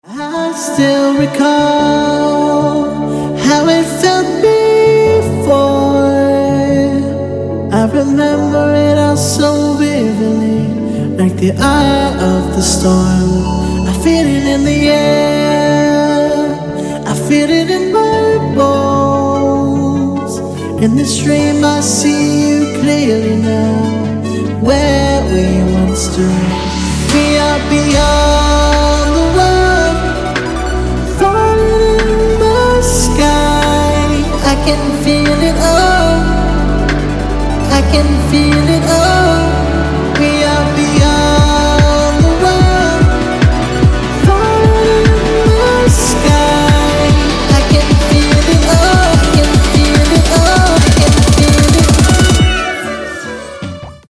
• Качество: 160, Stereo
поп
мужской вокал
Melodic
romantic
vocal